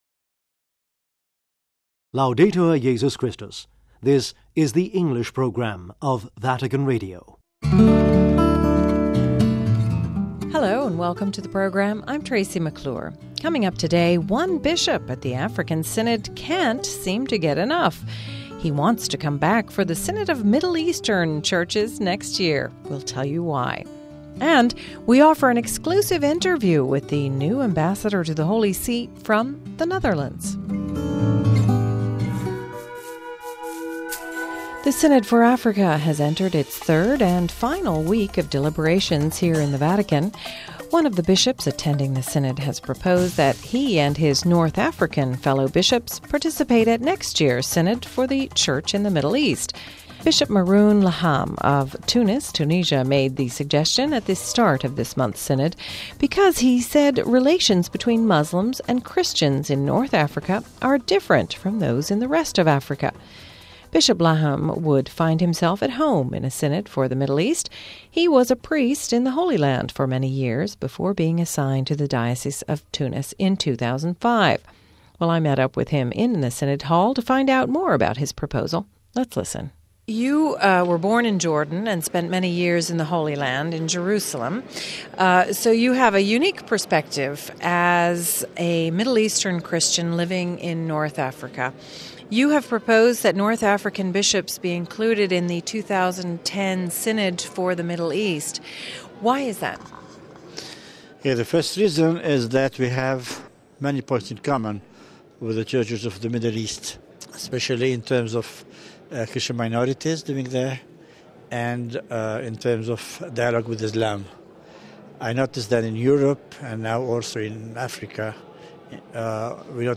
He wants to come back for the Synod of Middle Eastern Churches next year – we tell you why NEW AMBASSADOR FROM NETHERLANDS - We offer an exclusive interview with the new Dutch ambassador to the Holy See...